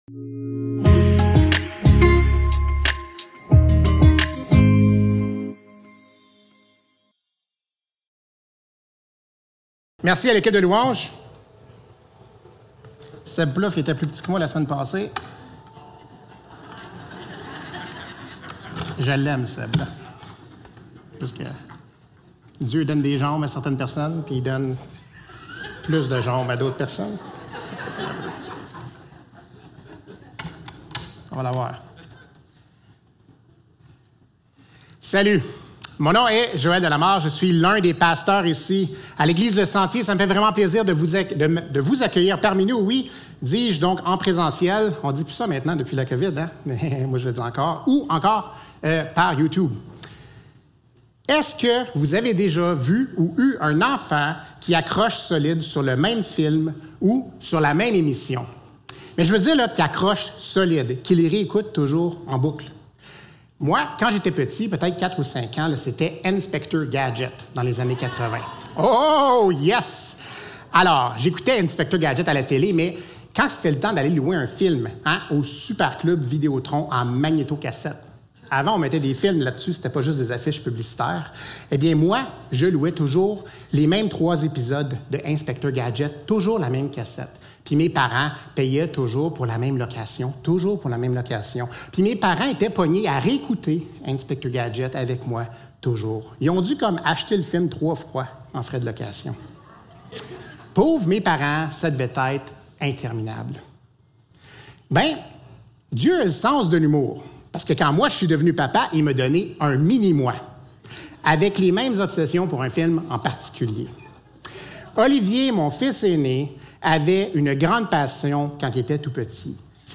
Matthieu 6.24-34 Service Type: Célébration dimanche matin Description